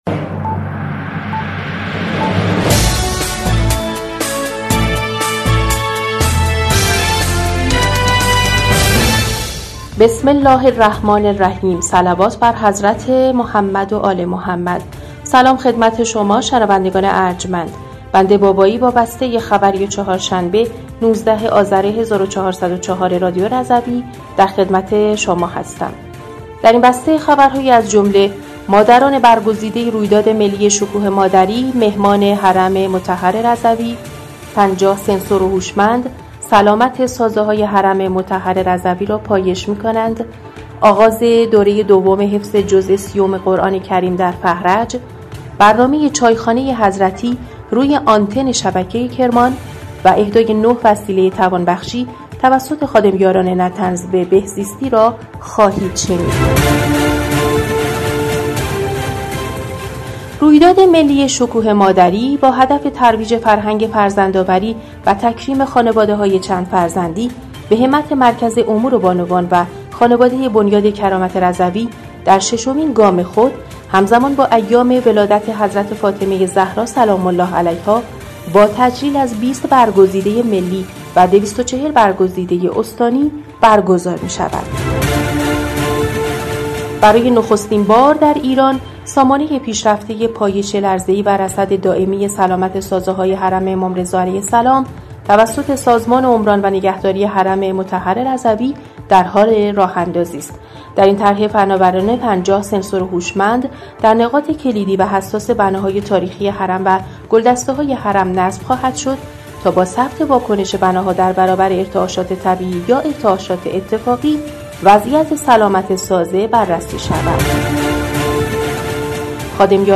بسته خبری ۱۹ آذر ۱۴۰۴ رادیو رضوی؛